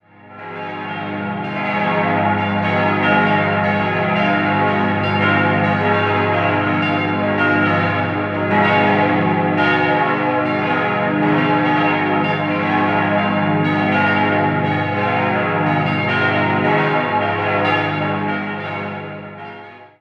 Lange Zeit benutzten beide Konfessionen ein gemeinsames Gotteshaus, bevor die evangelisch-reformierten Christen 1937 eine eigene Kirche mit Gemeindezentrum einweihen konnten. 5-stimmiges erweitertes Wachet-auf-Geläute: as°-c'-es'-f'-as' Alle Glocken wurden von der Gießerei Rüetschi in Aarau hergestellt.